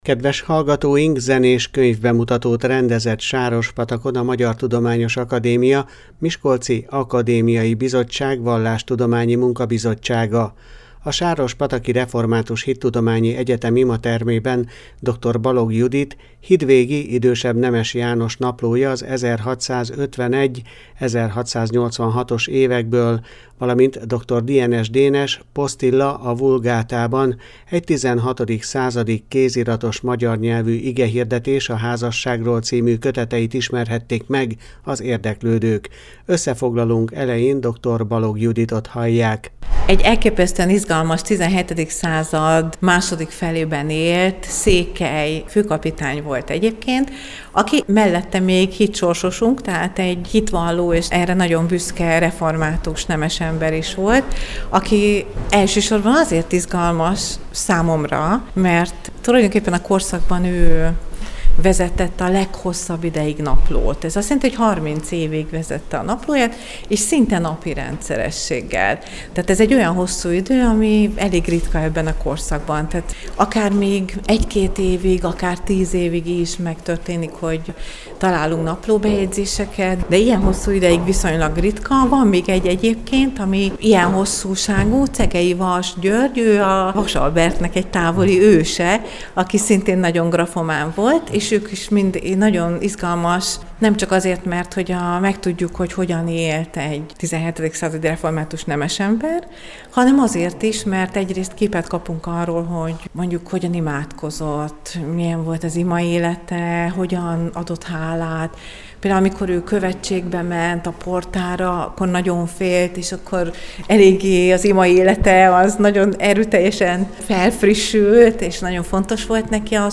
Zenés könyvbemutató a pataki hittudományi egyetemen
Zenés könyvbemutatót rendezett Sárospatakon a Magyar Tudományos Akadémia Miskolci Akadémiai Bizottság Vallástudományi Munkabizottsága.